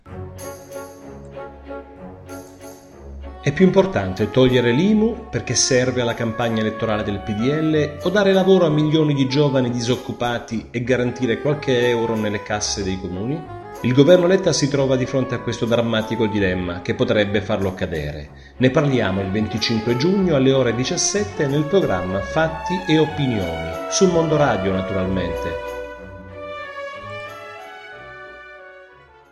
Documenti allegati: Promo della puntata